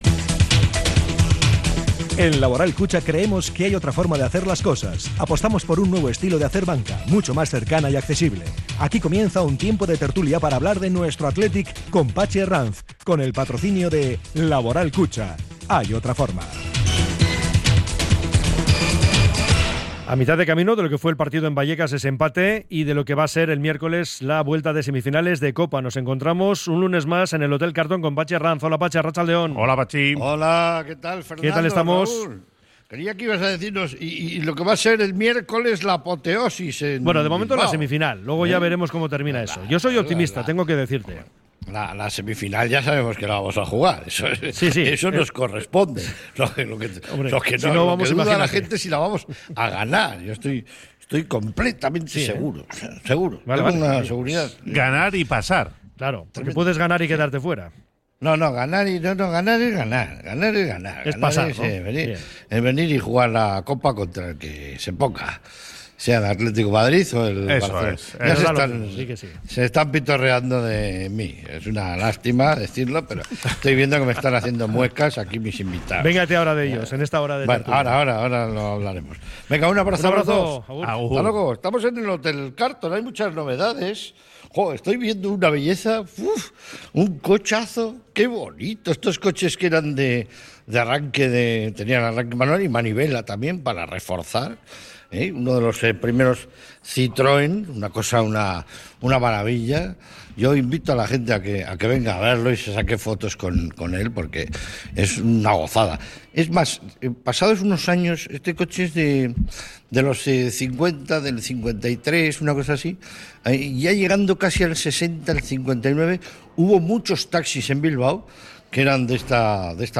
sus invitados desde el hotel Carlton